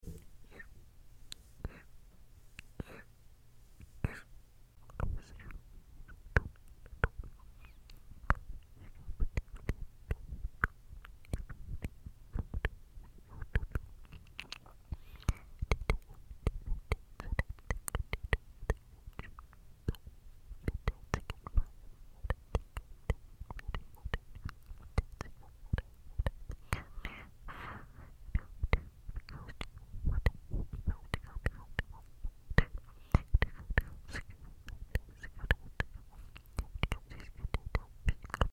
HD Mic Mouth Sounds For Sound Effects Free Download